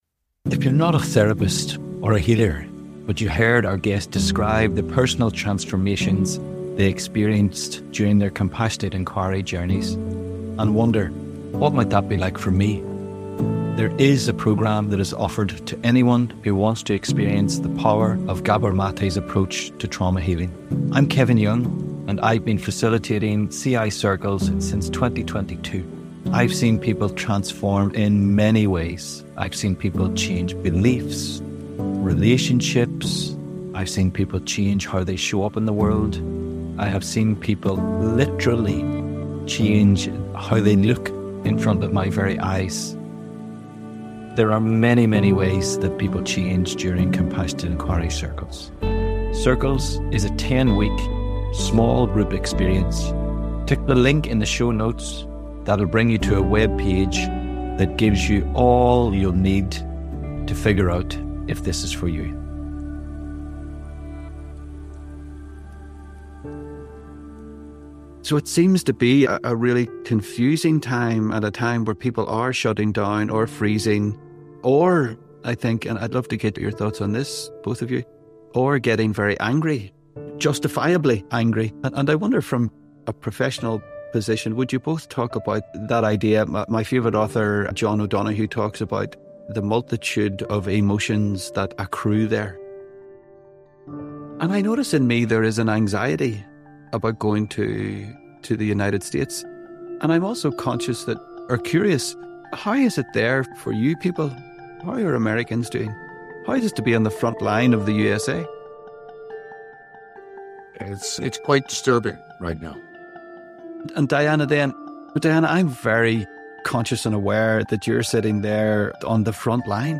It might sound like this conversation, which wanders —with warmth, humor, and hard-won wisdom—from grief to survival, from despair to agency, from the deeply personal to the global.